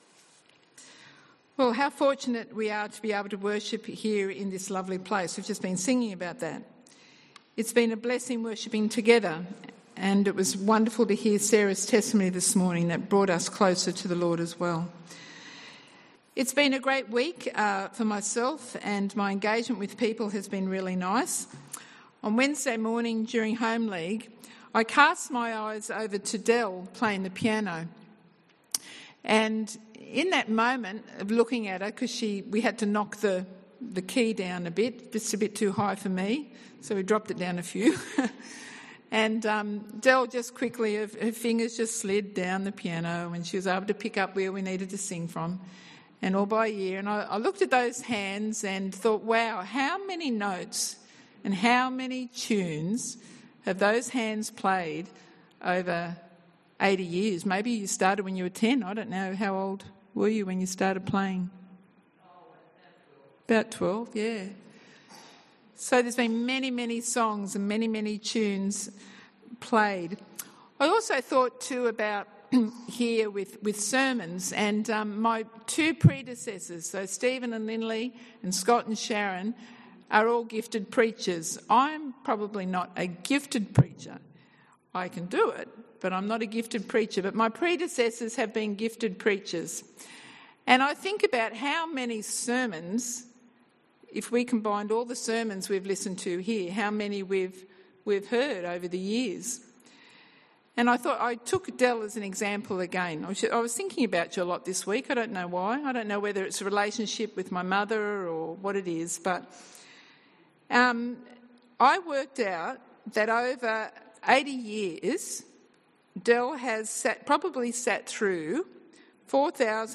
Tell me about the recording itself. Sermon from the 10AM meeting at Newcastle Worship & Community Centre of The Salvation Army.